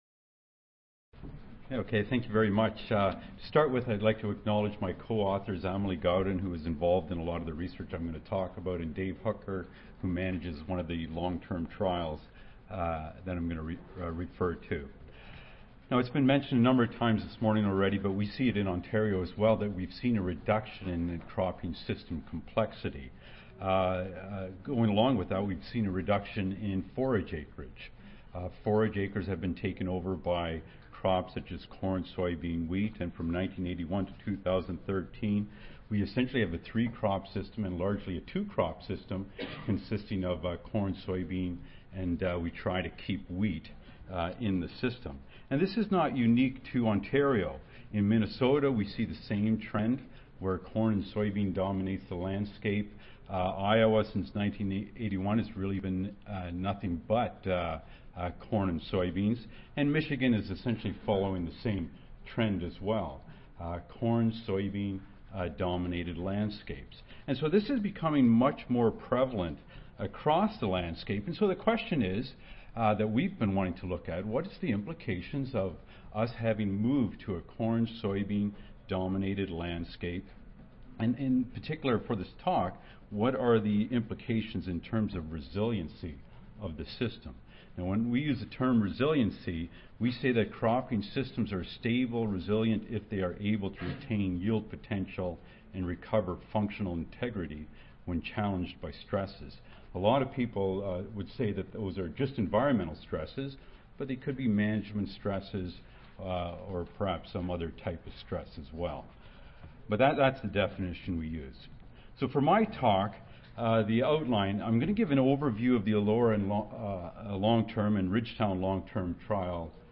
University of Guelph Audio File Recorded Presentation